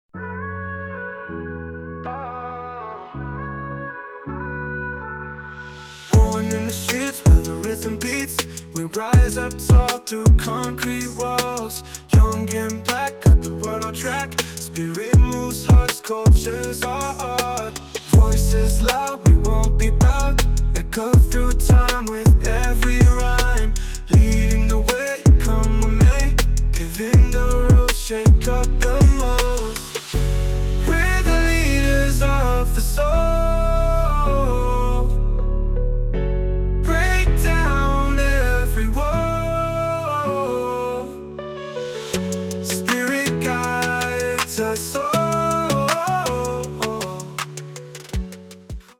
Short version of the song, full version after purchase.
An incredible R&B song, creative and inspiring.